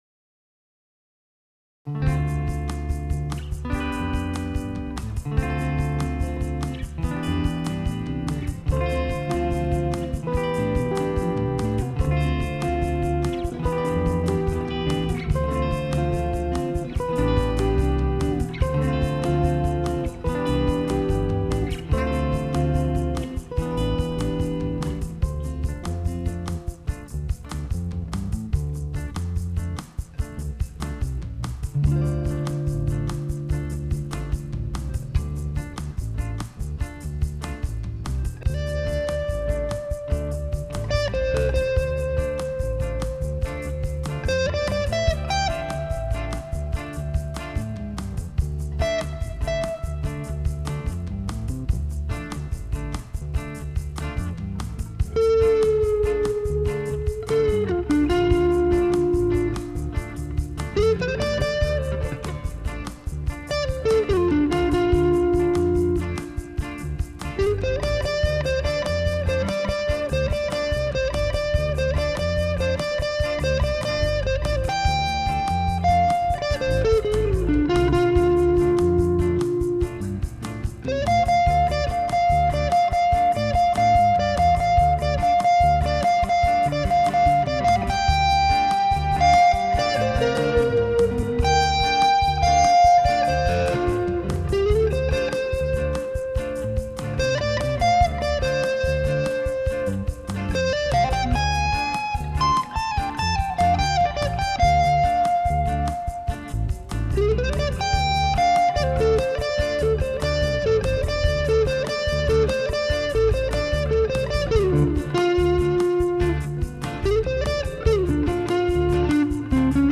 RHYTHMIQUE
BASSE
SOLO